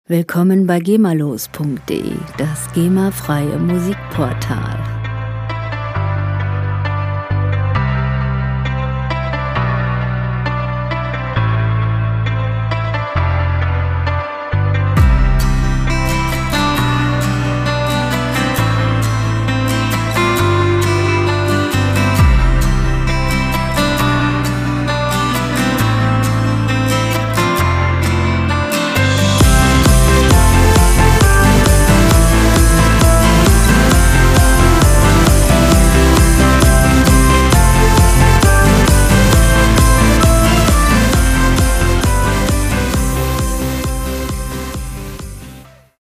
Werbemusik - Lifestyle
Musikstil: Pop
Tempo: 133 bpm
Tonart: C-Dur
Charakter: heiter, gut gelaunt
Instrumentierung: Gitarre, Oboe, Synthesizer